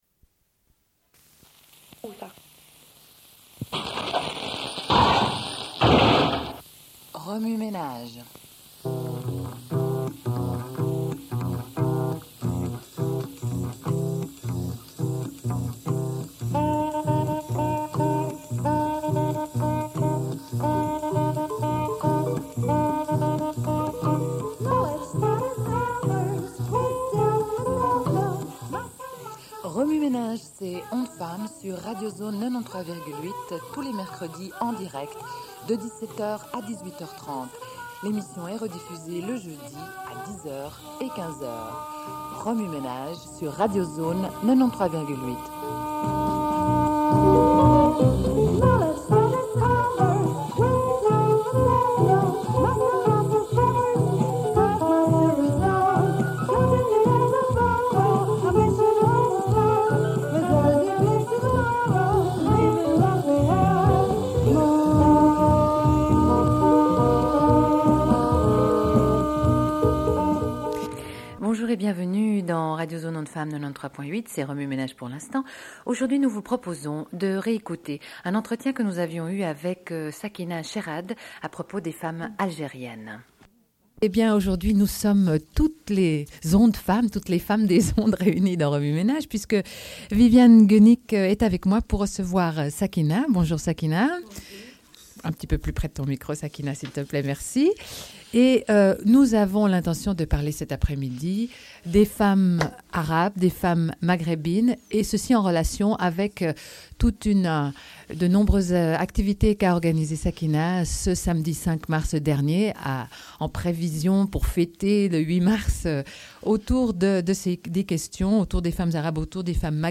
Une cassette audio, face A30:54